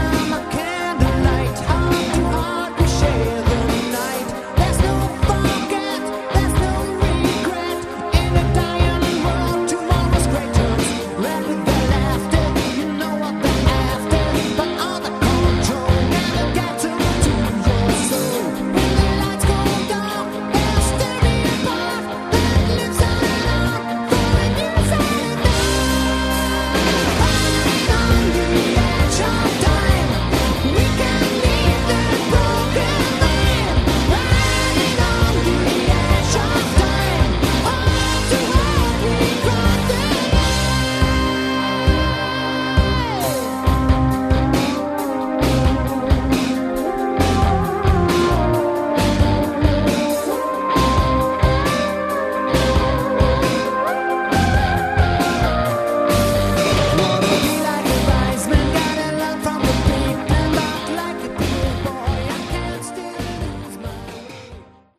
Category: AOR
vocals
guitars, keys, bass
drums